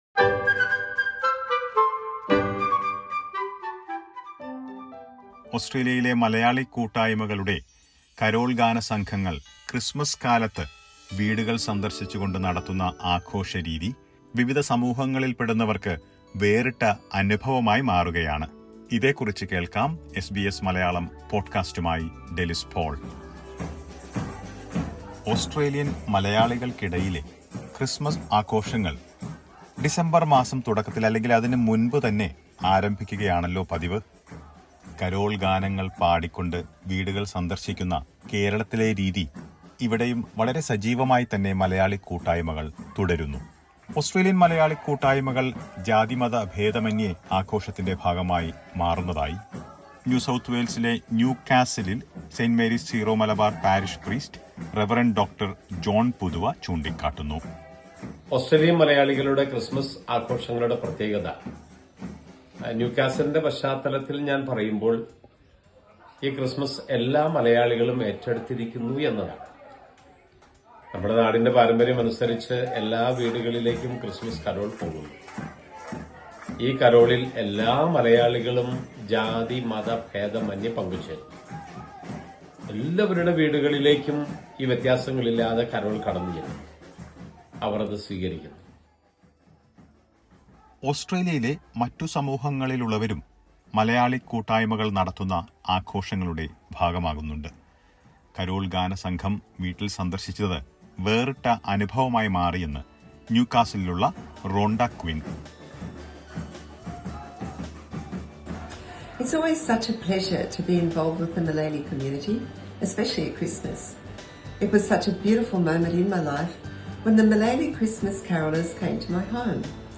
ക്രിസ്മസ്‌ക്കാലത്ത് വീട് വീടാന്തരം സന്ദർശിച്ച് കരോളുകൾ പാടുന്ന കേരളത്തിലെ രീതി ഓസ്‌ട്രേലിയയിലും സജീവമായി തുടരുകയാണ് മലയാളി കൂട്ടായ്‌മകൾ. ഓസ്‌ട്രേലിയൻ മലയാളികളുടെ ക്രിസ്മസ് ആഘോഷങ്ങൾ മറ്റു സമൂഹങ്ങളിൽപ്പെടുന്നവർക്ക് വേറിട്ട അനുഭവമായി മാറുന്നത് സംബന്ധിച്ചുള്ള റിപ്പോർട്ട് കേൾക്കാം മുകളിലെ പ്ലെയറിൽ നിന്ന്.